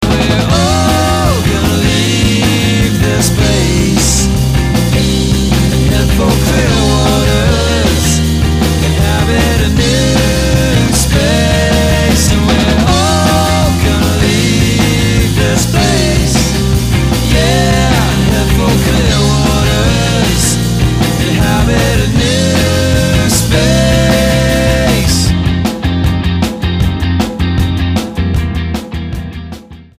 STYLE: Rock
Bristol-based four-piece